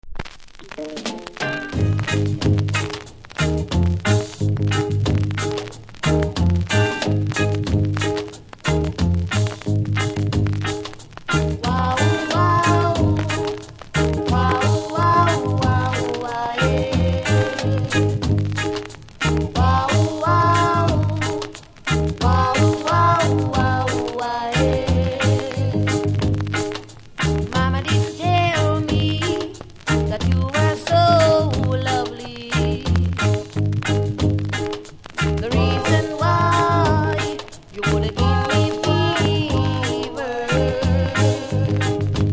キズ多めノイズ多めです。